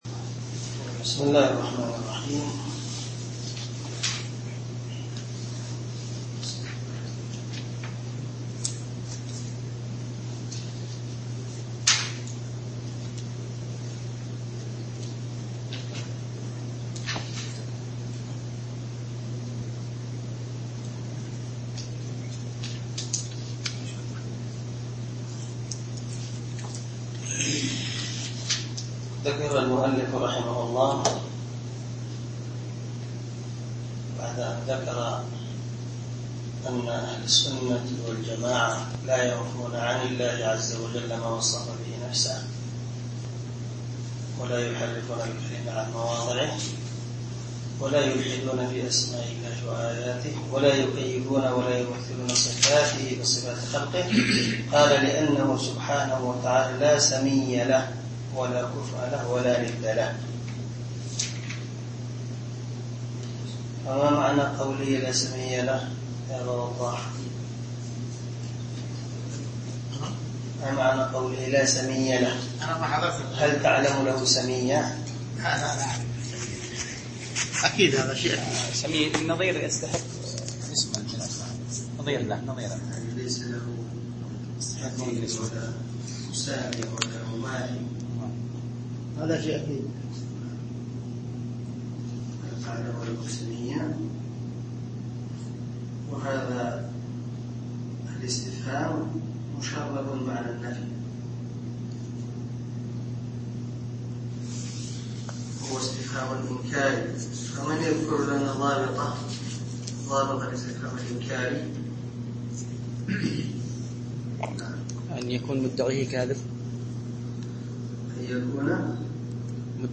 عنوان الدرس: الدرس الحادي عشر
دار الحديث- المَحاوِلة- الصبيحة.